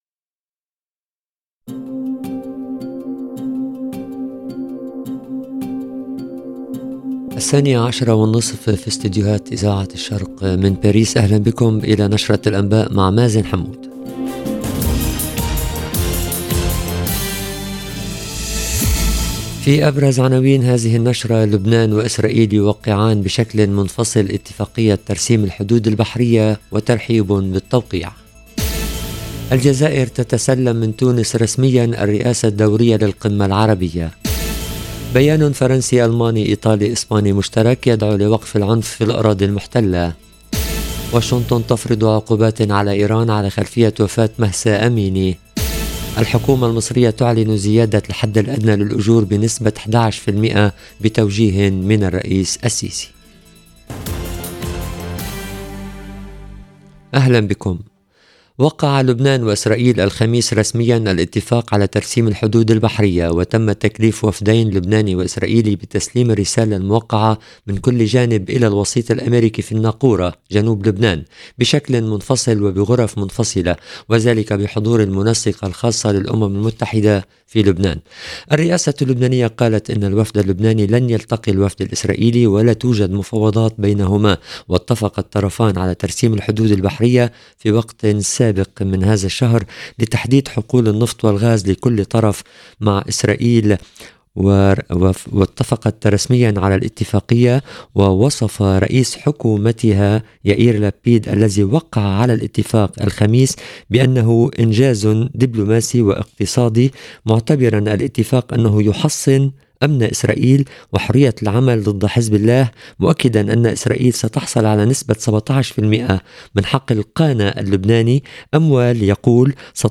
LE JOURNAL EN LANGUE ARABE DE MIDI DU 27/10/22